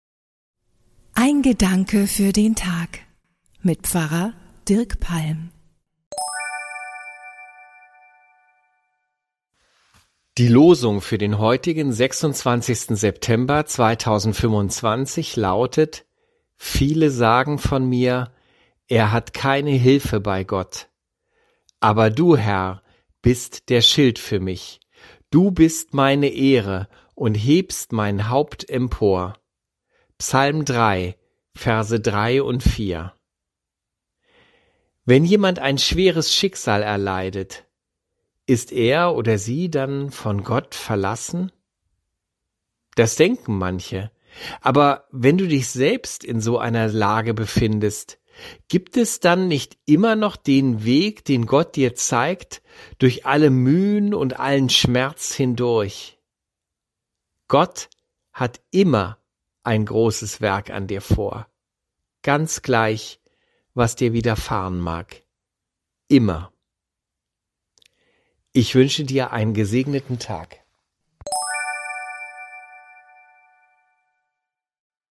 Pfarrer